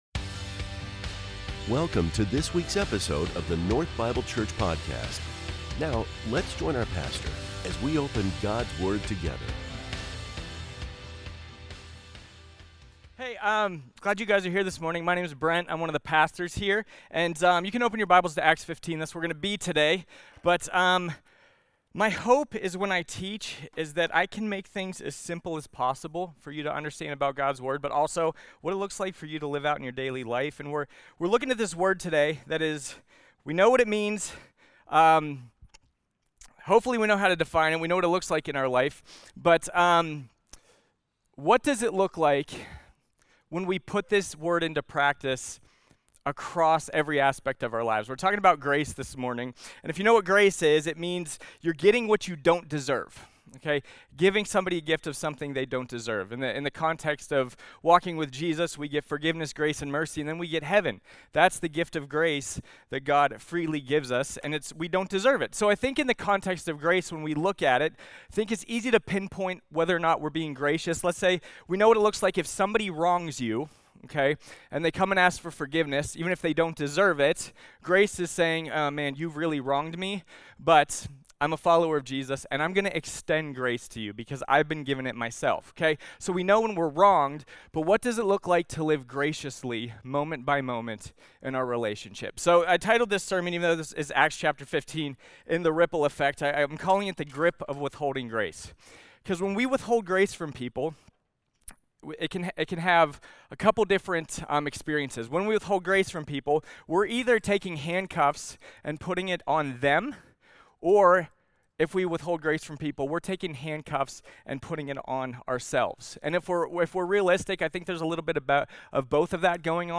Sermons from North Bible Church in Scottsdale, Arizona.